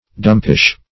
dumpish - definition of dumpish - synonyms, pronunciation, spelling from Free Dictionary
Search Result for " dumpish" : The Collaborative International Dictionary of English v.0.48: Dumpish \Dump"ish\, a. Dull; stupid; sad; moping; melancholy.